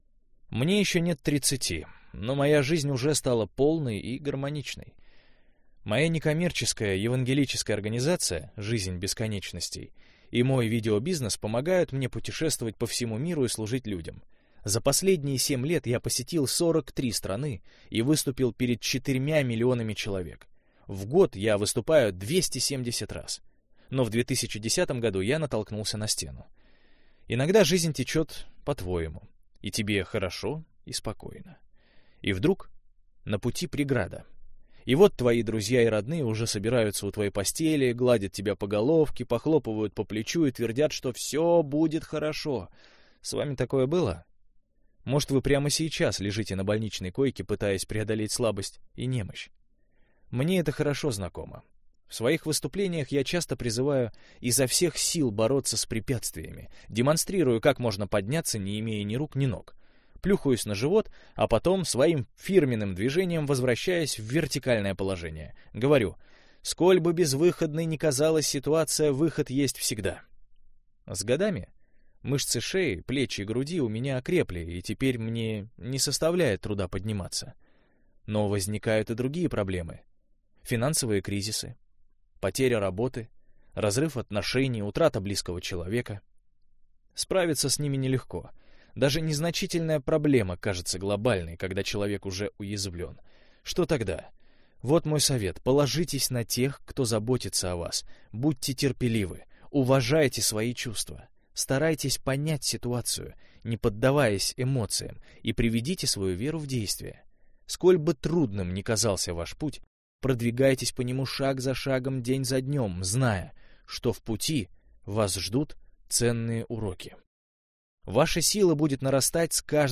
Аудиокнига Неудержимый.